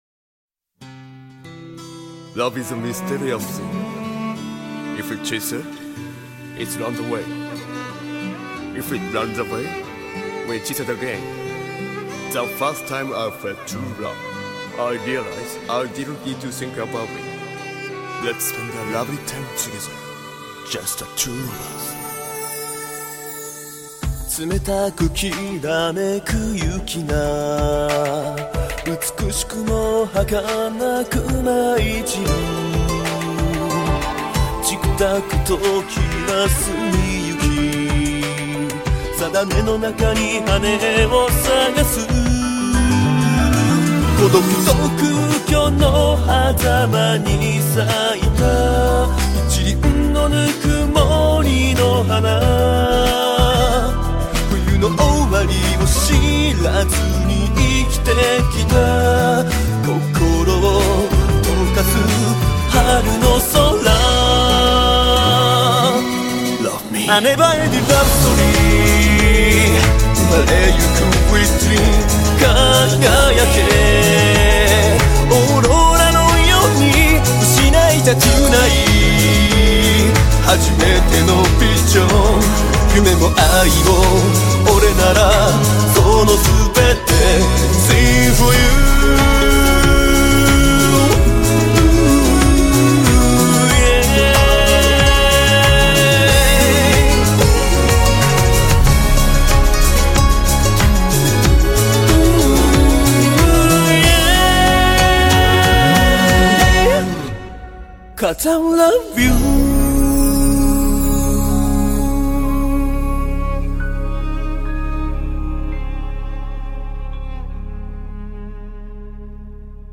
BPM46-93
Audio QualityPerfect (Low Quality)